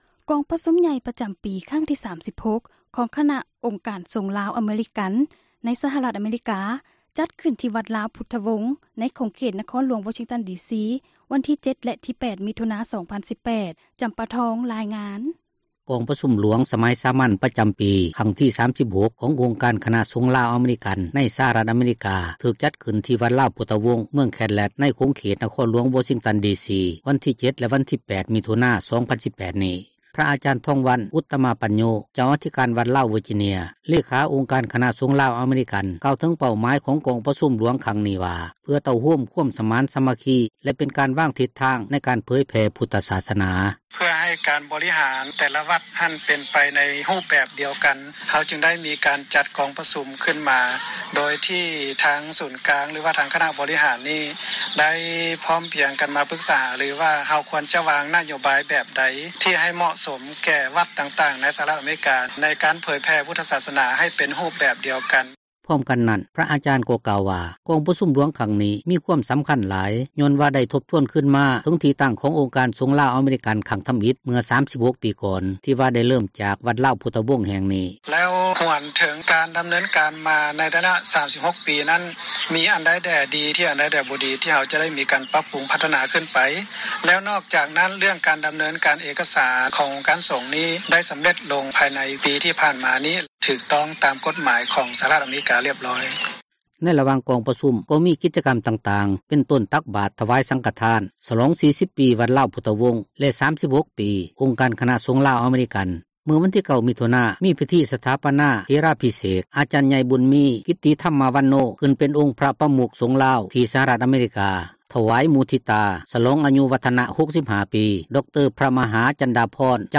ກອງປະຊຸມຄັ້ງທີ 36 ຄນະສົງ ລາວ-ອາເມຣິກັນ — ຂ່າວລາວ ວິທຍຸເອເຊັຽເສຣີ ພາສາລາວ